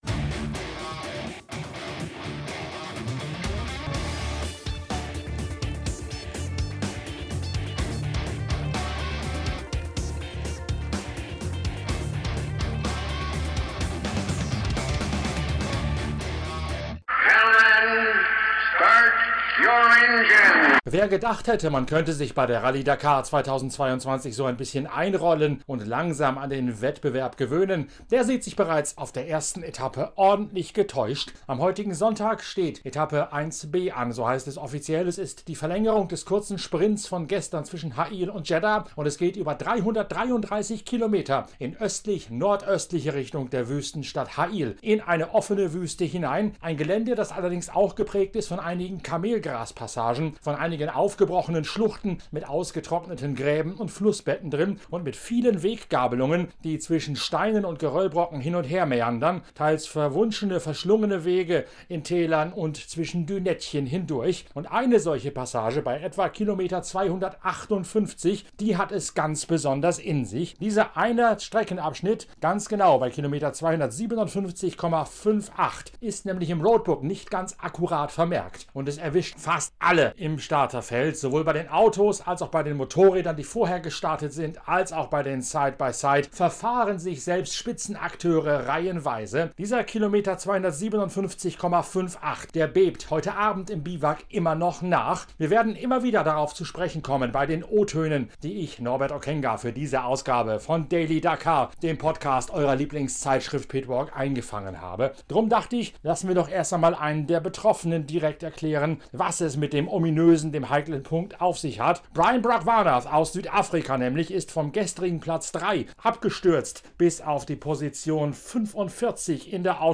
In dieser Analyse kommen alle wichtigen Akteure von Autos, Motorrädern und Side-by-Sides ausgiebig zu Wort.